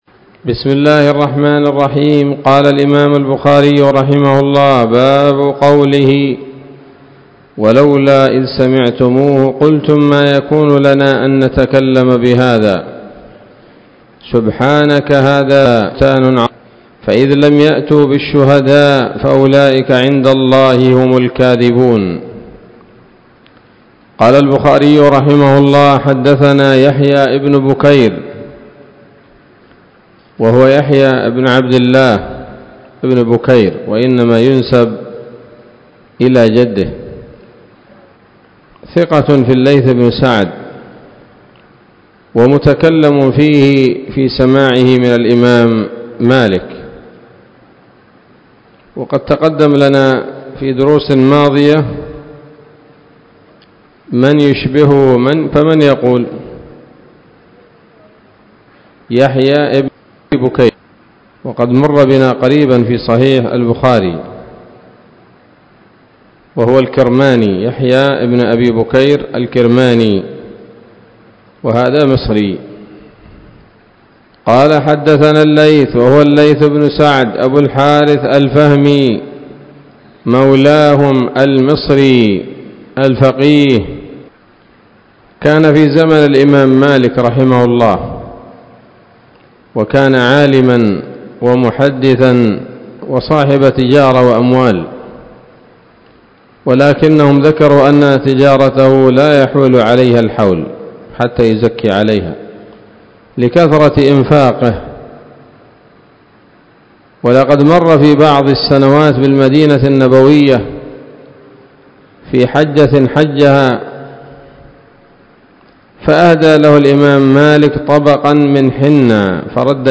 الدرس الثالث والثمانون بعد المائة من كتاب التفسير من صحيح الإمام البخاري